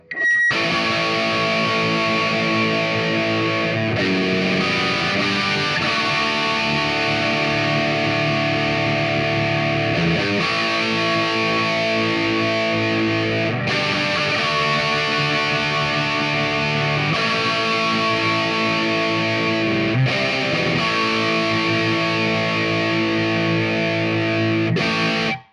Même rig que précédemment (Junior + Superlead), mais avec une pédale RAT de chez BYOC (en mode MOSFET):
Les Paul Jr, Marshall Superlead et RAT Mosfet.mp3